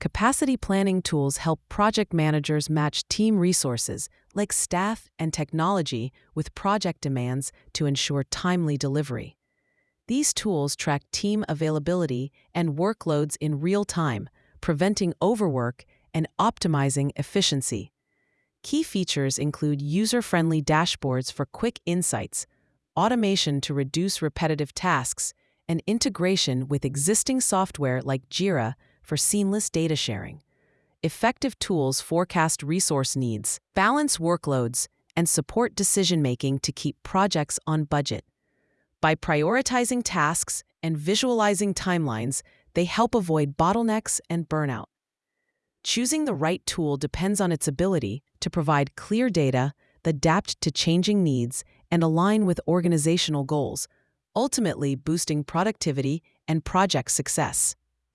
Audio_summary_of_advanced_capacity_planning_tools.mp3